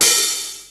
Hat Open_21.wav